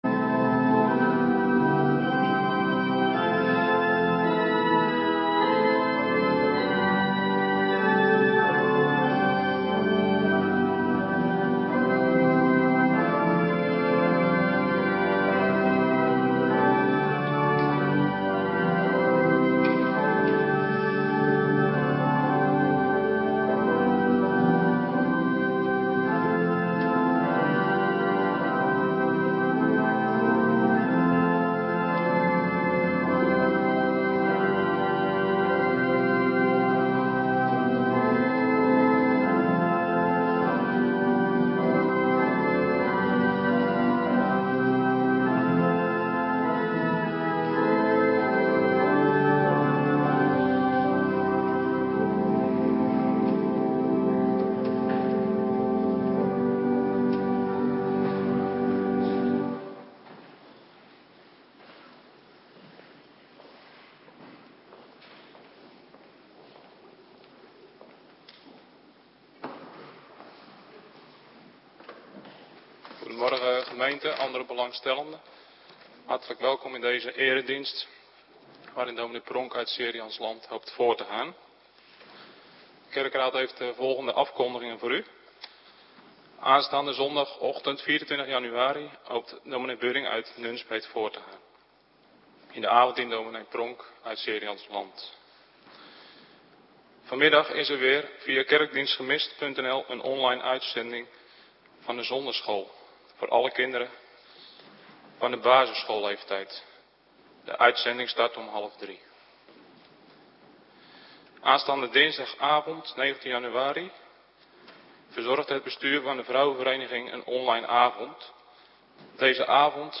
Morgendienst - Cluster 1
Locatie: Hervormde Gemeente Waarder